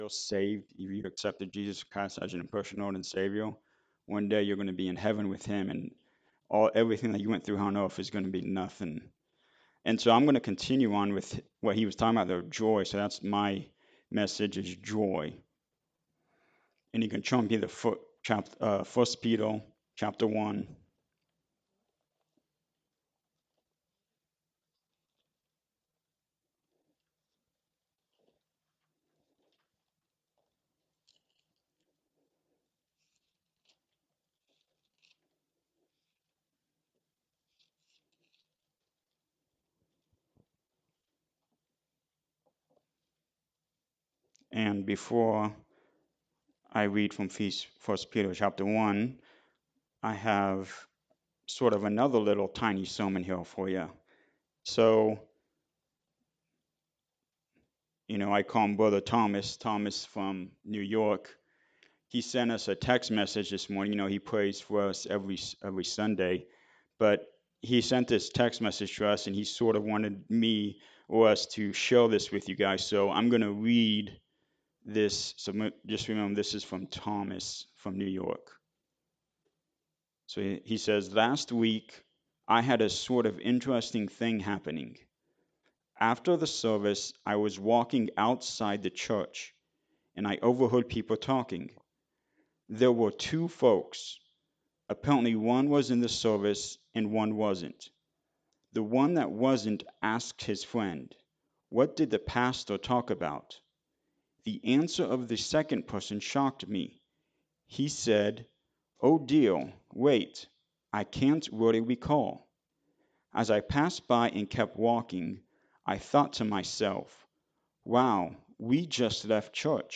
1 Peter 1:3-7 Service Type: Sunday Morning Service We have struggles.
Sunday-Sermon-for-June-1-2025.mp3